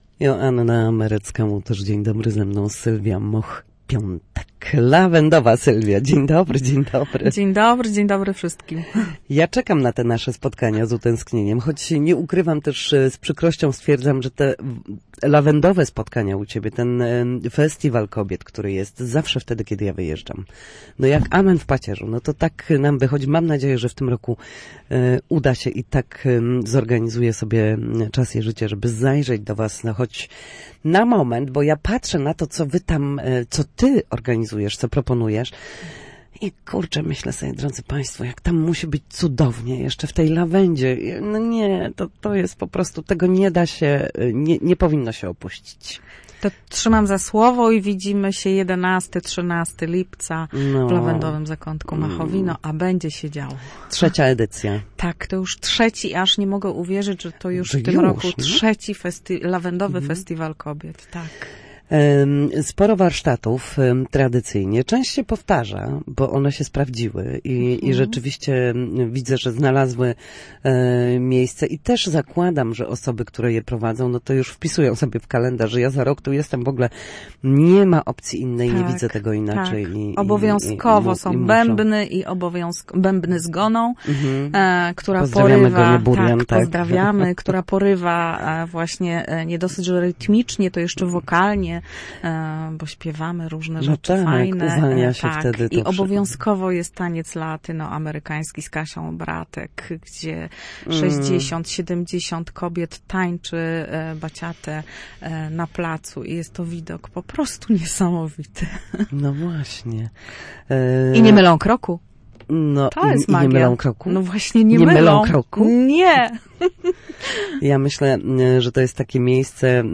Na naszej antenie opowiadała o kolejnej edycji Lawendowego Festiwalu Kobiet, wydarzenia pełnego śmiechu, magii, warsztatów i twórczych spotkań.